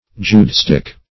Judaistic \Ju`da*is"tic\, a.